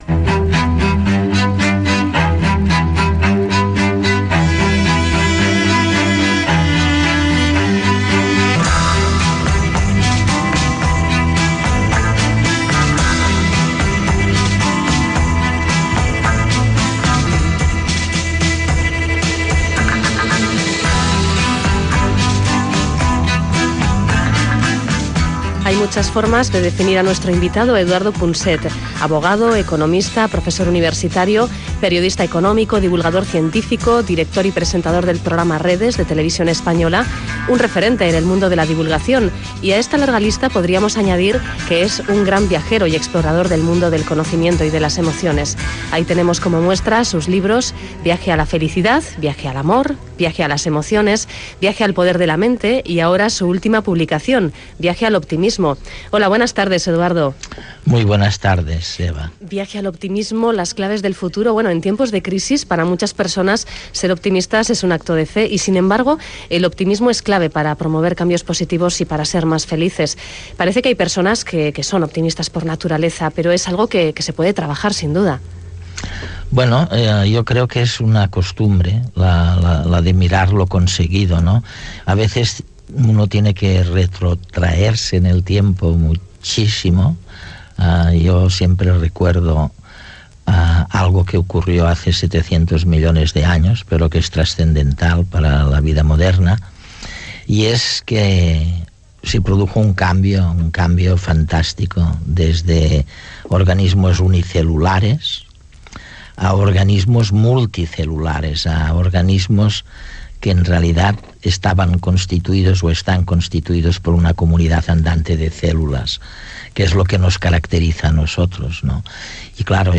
En esta charla reflexionó sobre gestión de las emociones, política y sobre el futuro de la divulgación.